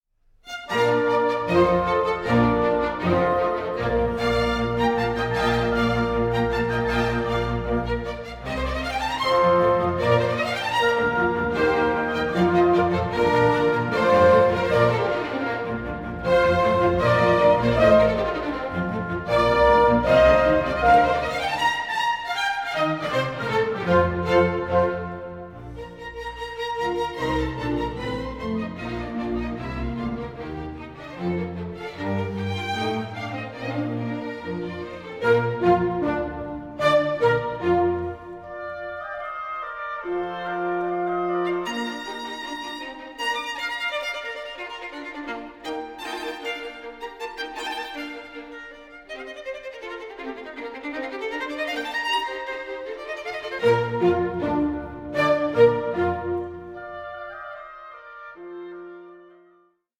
Concerto for Violin & Orchestra No. 1 in B-Flat Major
Presto 5:55